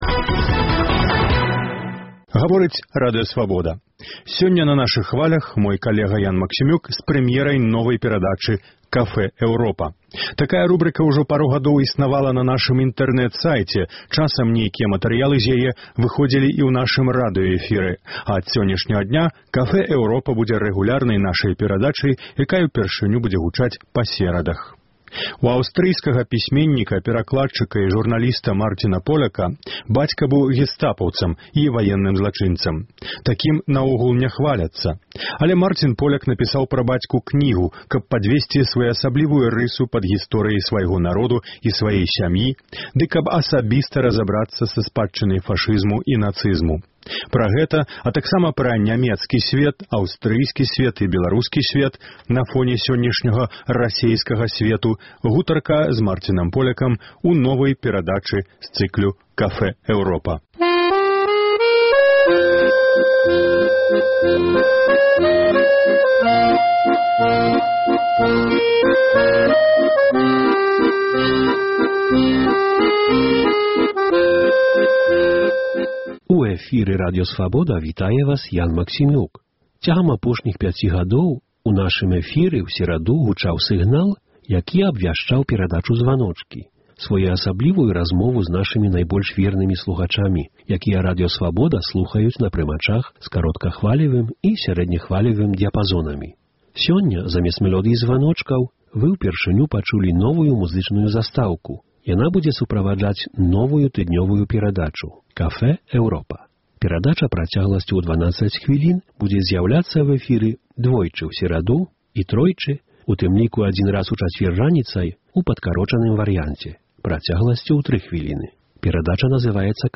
Але Марцін Поляк напісаў пра бацьку кнігу, каб падвесьці своеасаблівую рысу пад гісторыяй свайго народу і сваёй сям’і ды каб асабіста разабрацца са спадчынай фашызму і нацызму. Пра гэта, а таксама пра «нямецкі сьвет», «аўстрыйскі сьвет» і «беларускі сьвет» – на фоне сёньняшняга «рускага сьвету» – гутарка з Марцінам Полякам у новай эфірнай перадачы з цыклу Café Europa.